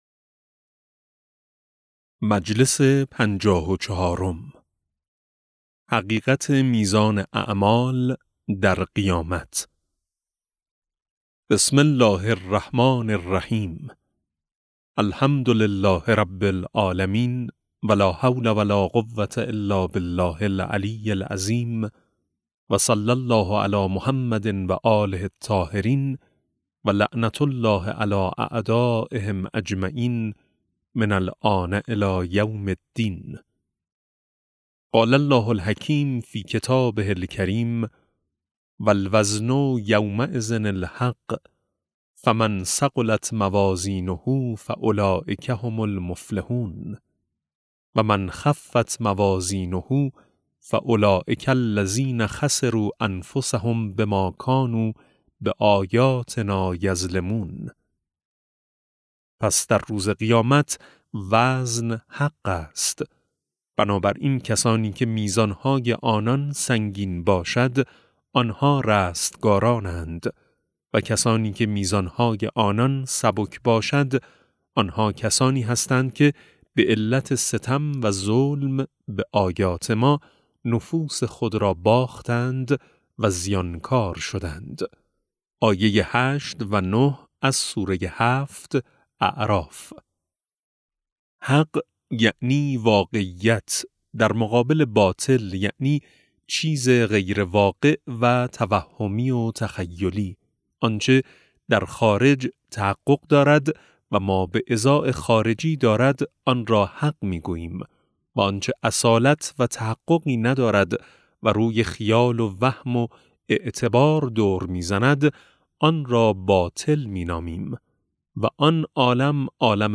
کتاب صوتی معاد شناسی ج8 - جلسه4